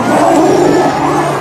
wheel_spinning_loop_01.ogg